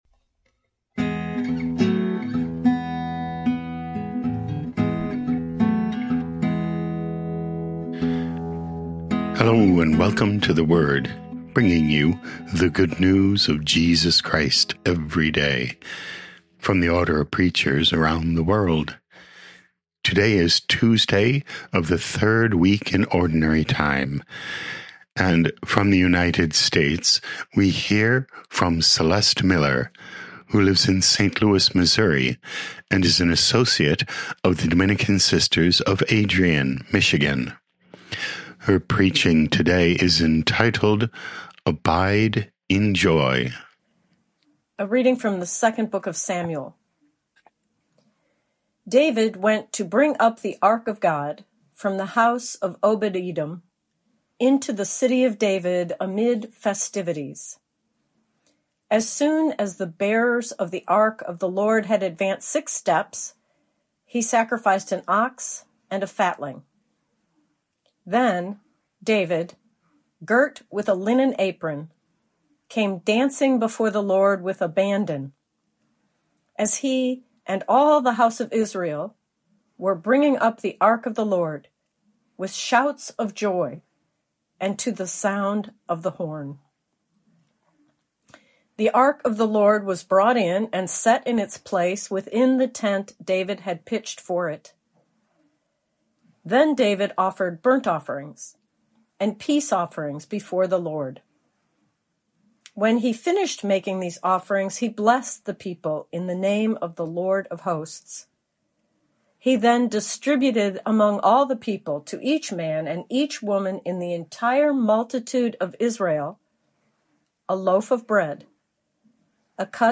27 Jan 2026 Abide in Joy Podcast: Play in new window | Download For 27 January 2026, Tuesday of week 3 in Ordinary Time, based on 2 Samuel 6:12-15,?17-19, sent in from St. Louis, Missouri.
Preaching